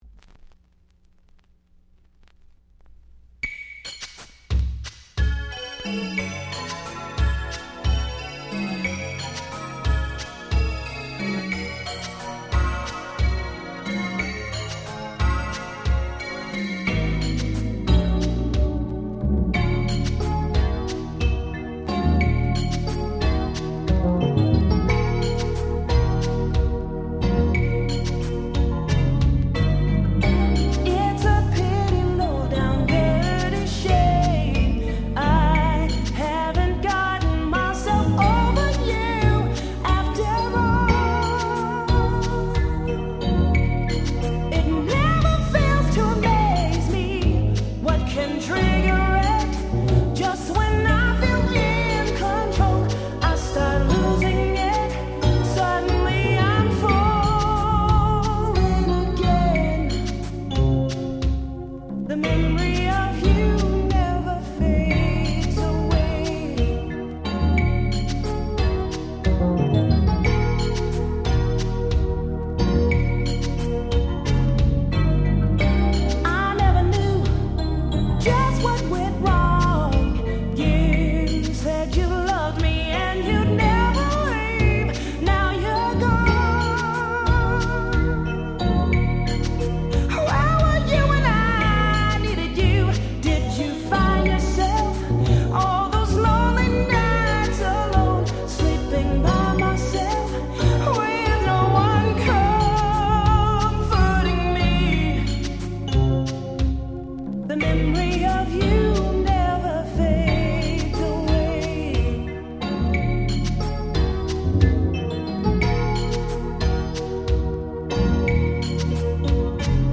90' Nice New Jack Swing/R&B !!
女性Vocalの切なく哀愁漂う超マイナーNJS !!